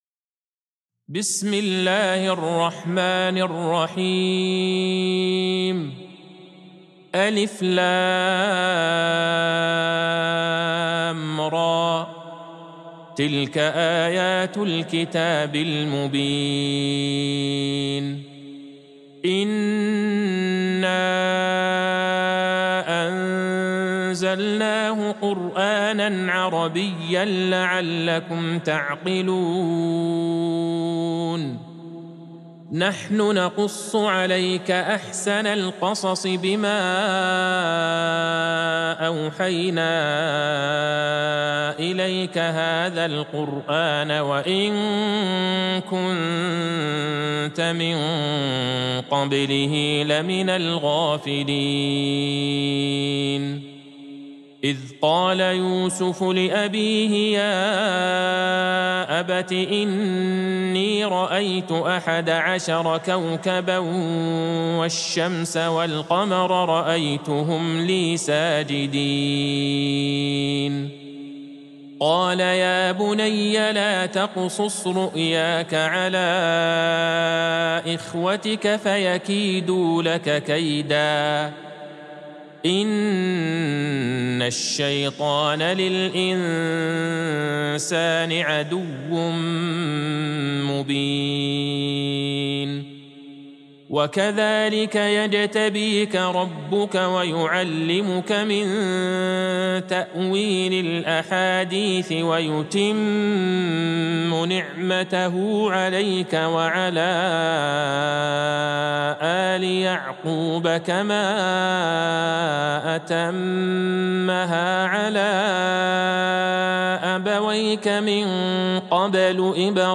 سورة يوسف Surat Yusuf | مصحف المقارئ القرآنية > الختمة المرتلة ( مصحف المقارئ القرآنية) للشيخ عبدالله البعيجان > المصحف - تلاوات الحرمين